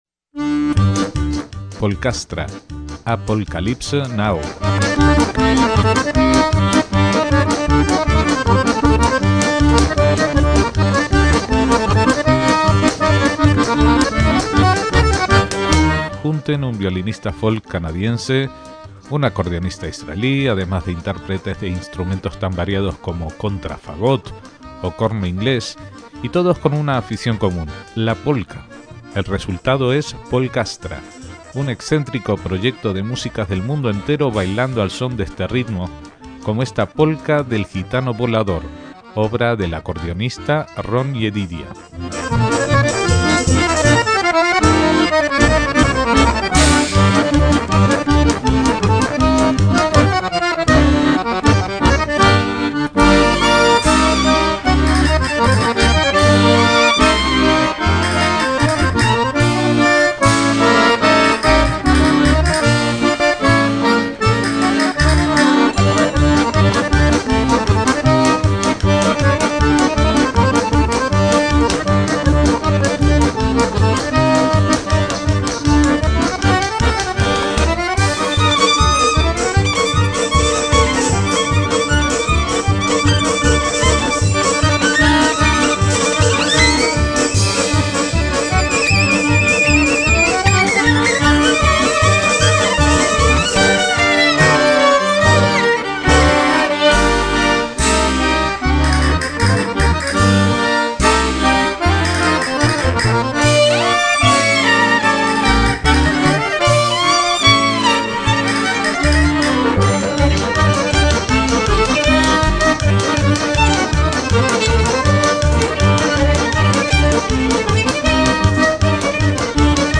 trompeta violín, guitarra y voces
contrabajo y fagot
vibráfono y percusión
didgeridoo